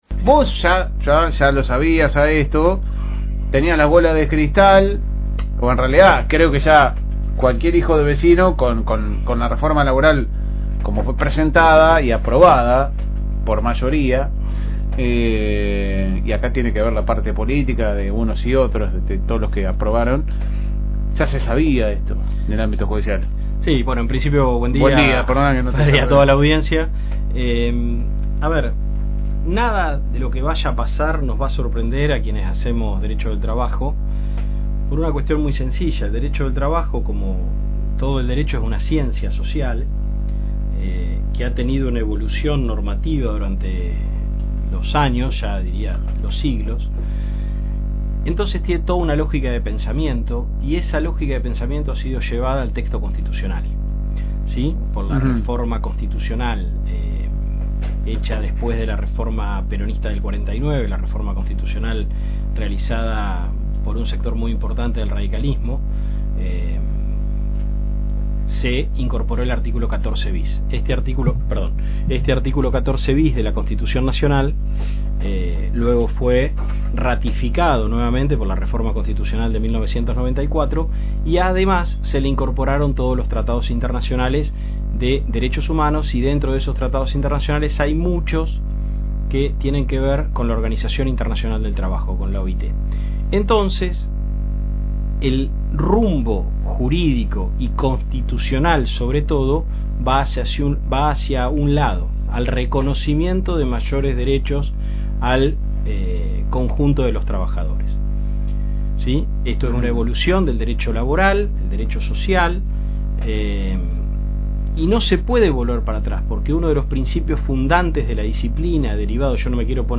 A lo largo de la entrevista, se profundizaron los detalles sobre cómo estas modificaciones alteran el día a día de las relaciones laborales y qué deben tener en cuenta las empresas locales ante este nuevo escenario legal.